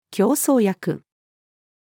強壮薬-female.mp3